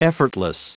Prononciation du mot effortless en anglais (fichier audio)
Prononciation du mot : effortless